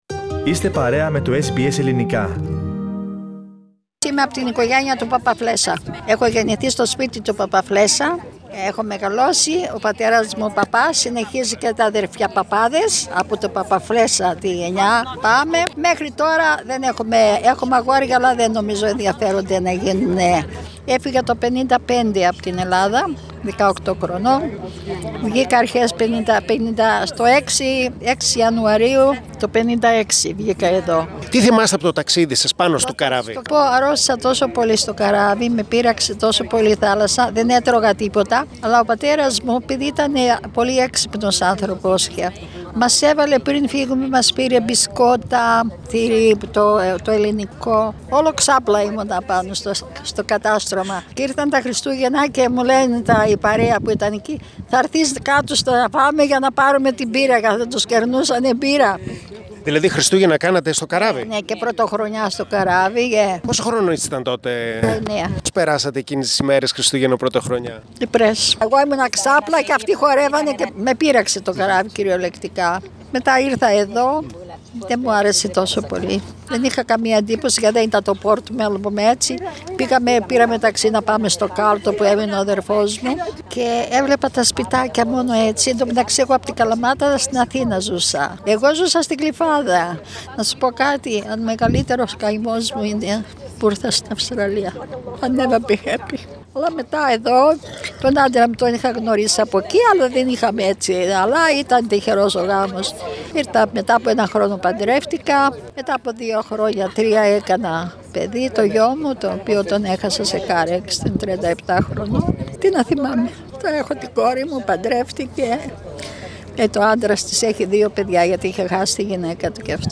Στις αρχές Μαρτίου του 2020, λίγες ημέρες πριν επιβληθεί το μέτρο της απαγόρευση των συγκεντρώσεων εξαιτίας του κορωνοϊού, πραγματοποιήθηκε στην προβλήτα Princes Pier της Μελβούρνης αντάμωμα Ελλήνων μεταναστών που έφτασαν στην Αυστραλία με τα καράβια «Πατρίς», «Ελληνίς» και «Αυστραλίς».